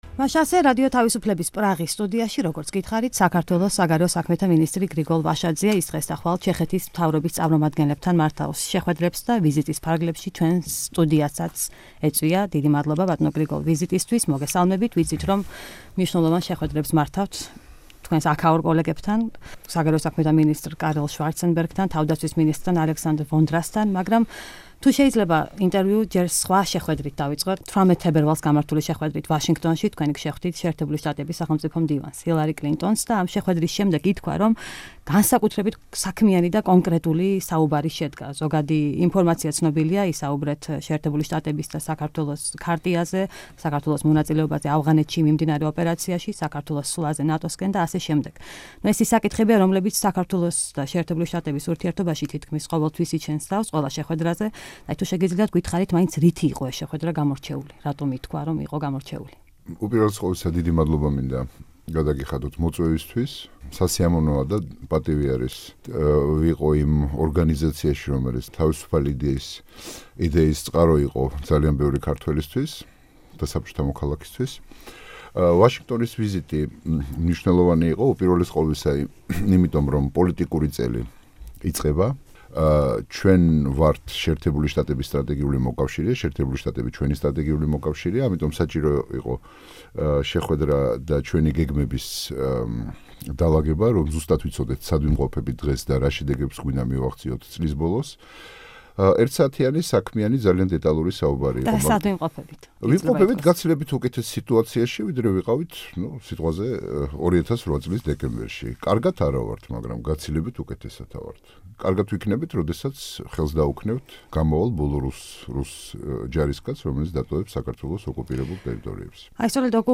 ინტერვიუ გრიგოლ ვაშაძესთან